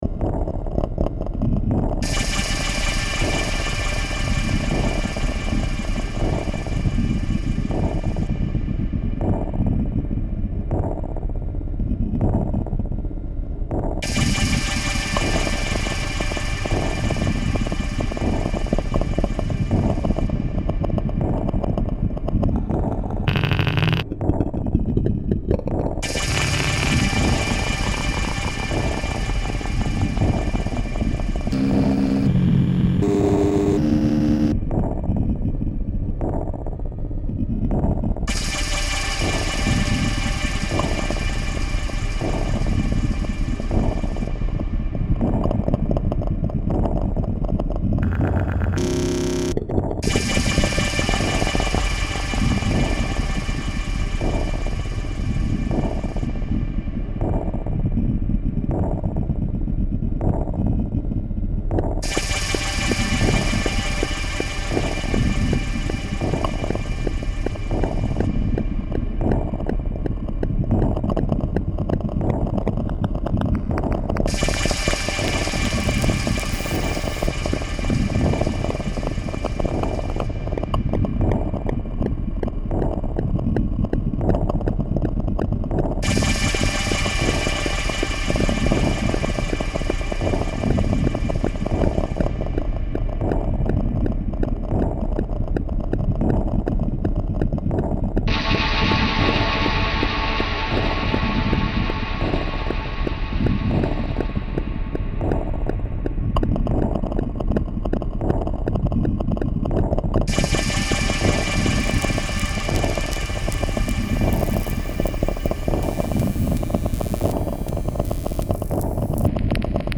plays guitars on track 08 and 11